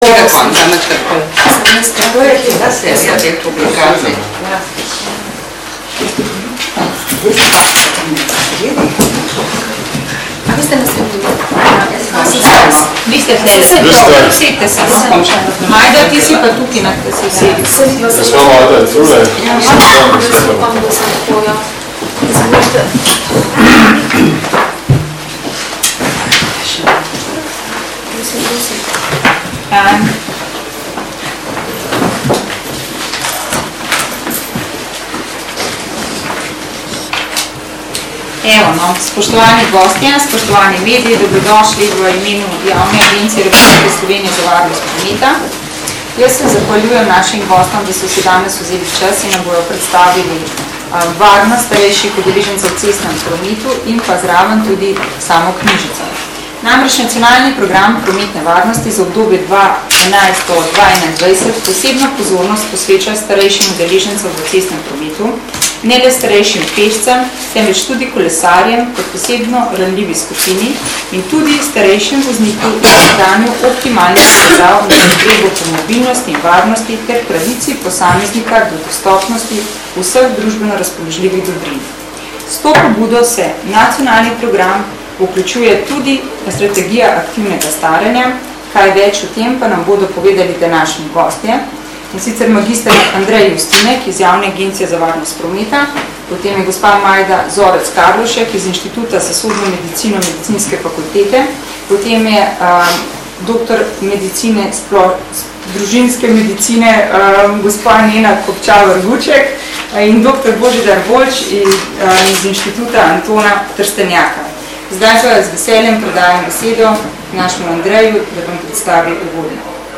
Danes, 29. novembra 2011 je v prostorih Javne agencije RS za varnost prometa potekala novinarska konferenca, ki se je osredotočila na tematiko starejših udeležencev, predvsem voznikov motornih vozil, v cestnem prometu.